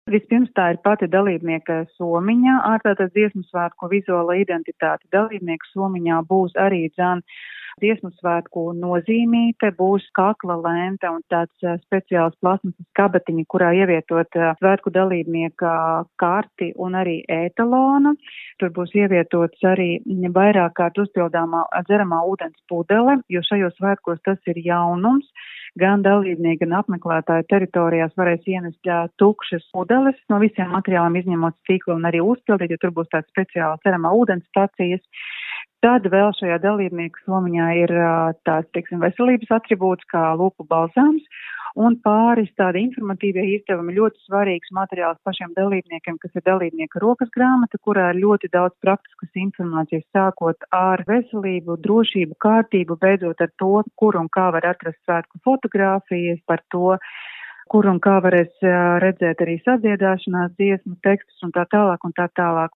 RADIO SKONTO Ziņās par to, kas atrodas Dziesmu un Deju svētku dalībnieku komplektā